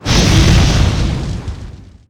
SFX龙的火焰攻击音效下载
SFX音效